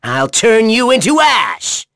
Neraxis-Vox_Skill5.wav